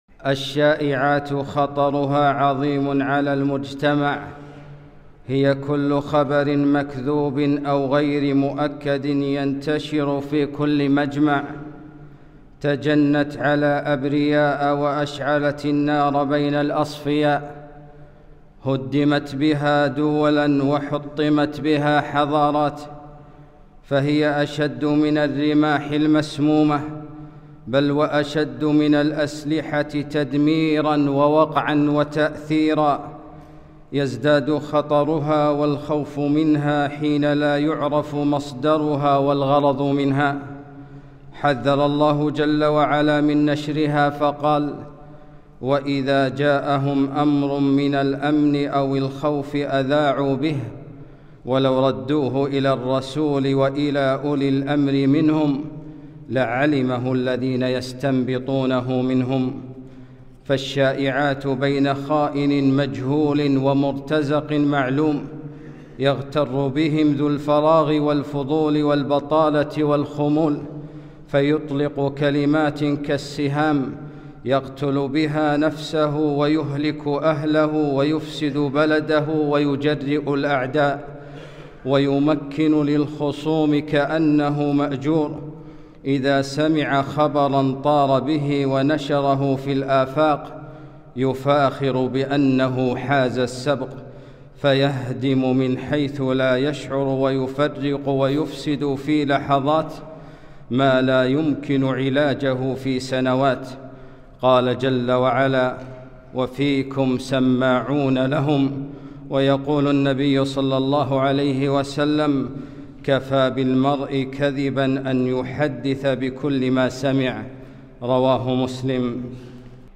خطبة - خائن مجهول ومرتزق معلوم